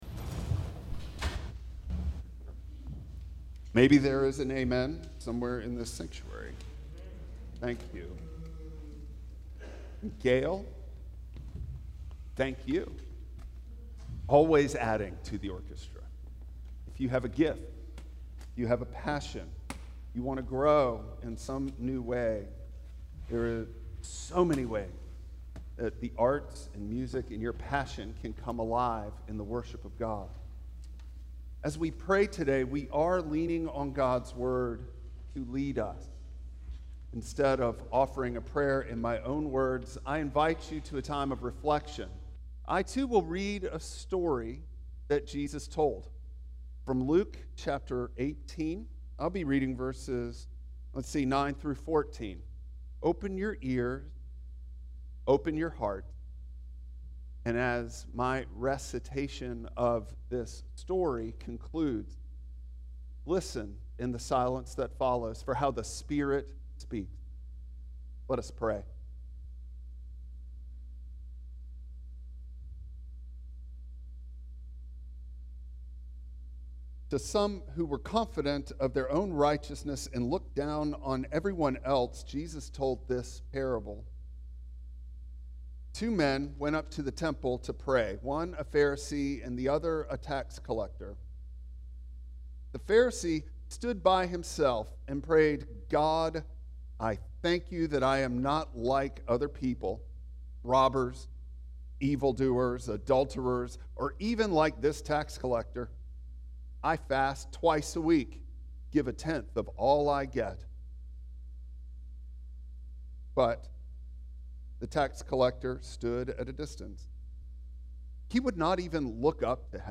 Nehemiah 9:1-5 Service Type: Traditional Service Bible Text